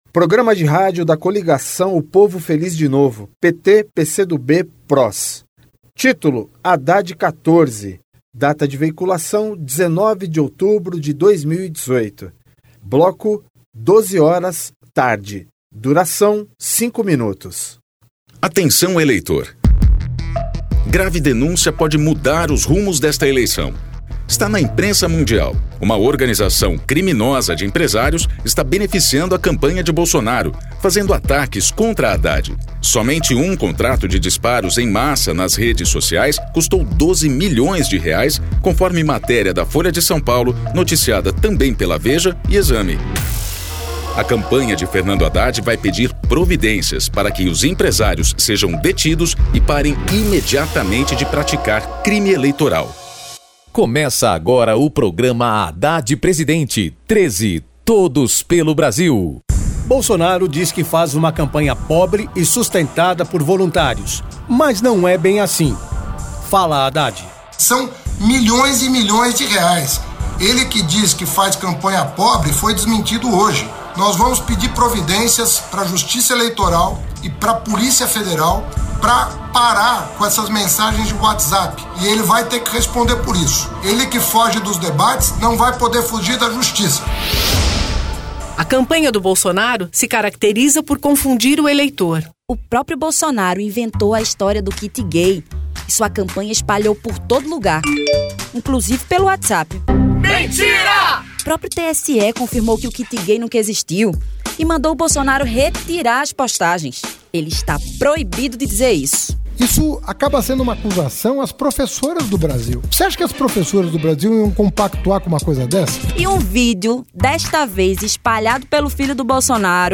Descrição Programa de rádio da campanha de 2018 (edição 44), 2º Turno, 19/10/2018, bloco 12hrs.